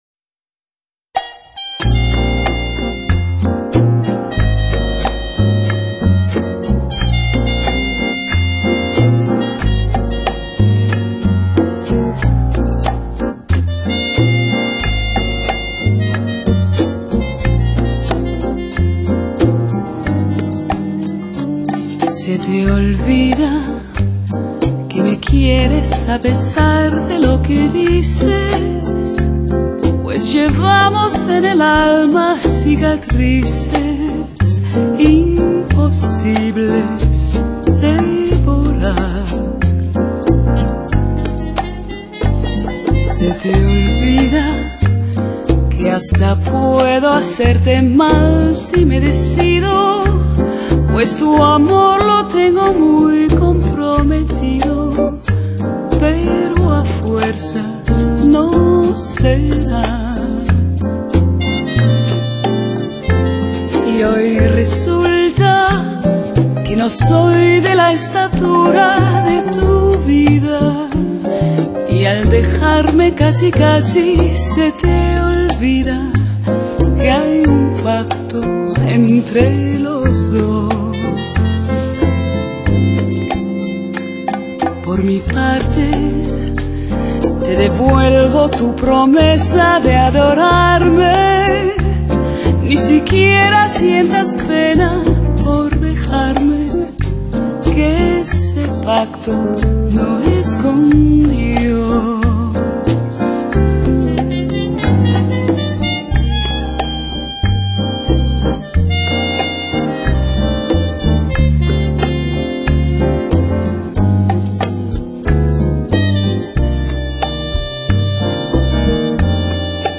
拉丁风情的曼妙JAZZ……
平易近人的旋律搭配上她温暖轻松的唱腔，让歌迷们感到温暖贴心和舒适。
火热而纯正的伦巴、恰恰、莎莎、波列罗节奏